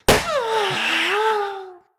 balloon_ghost_pop_02.ogg